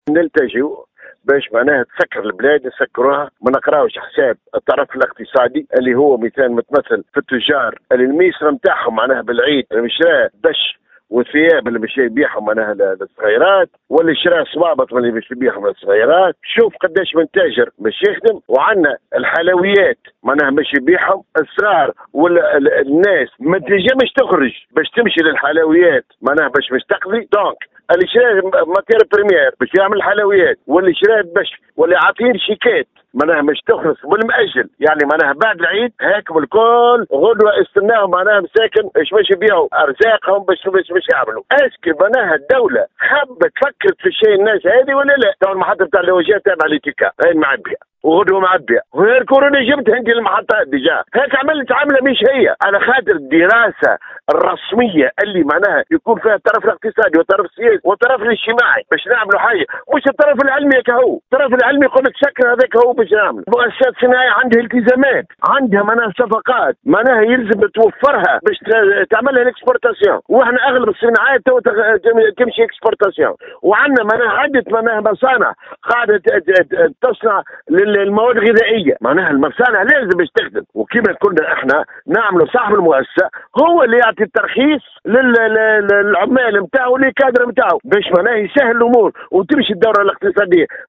تصريح